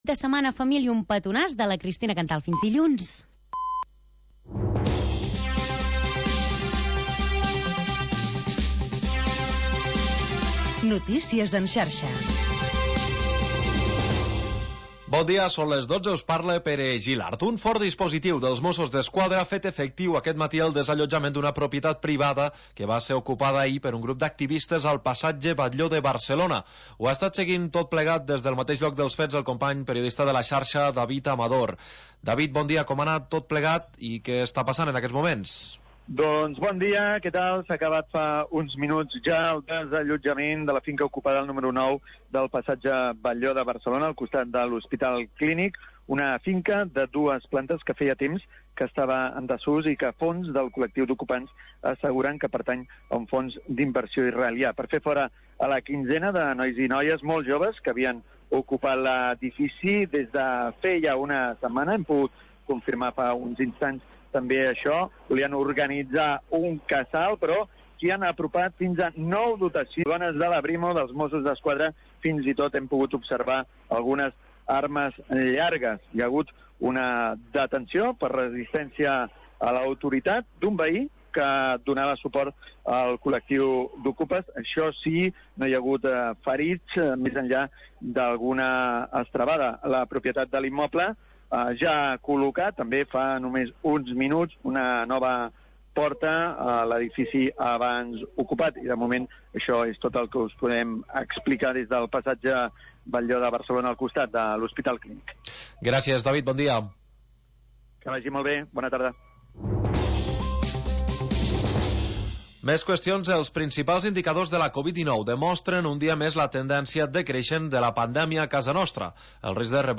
Tercera hora musical del magazín local d'entreteniment